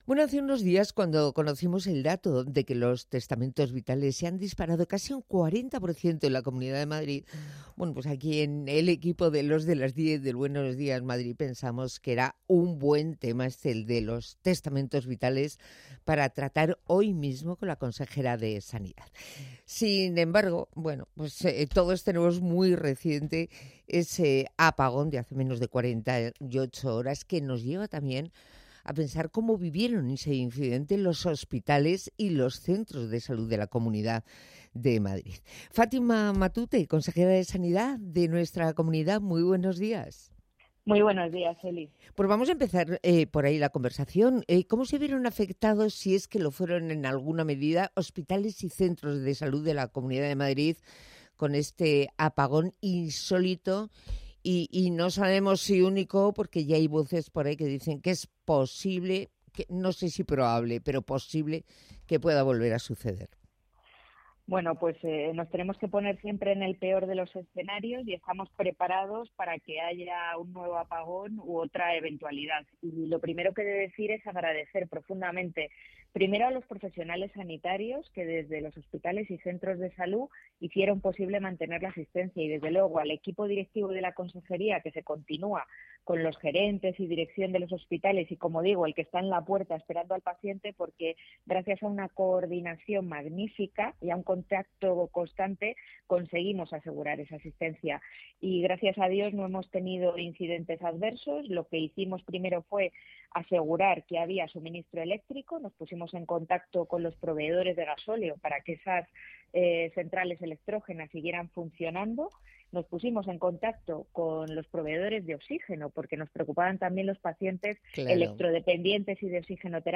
La Comunidad de Madrid enfrentó con éxito el apagón eléctrico del pasado 28 de abril, según ha explicado Fátima Matute, consejera de Sanidad, en una entrevista en Buenos Días Madrid de Onda Madrid.